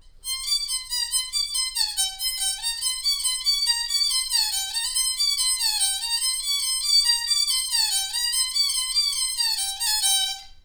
【二弦】演奏技法 单催 第二遍.wav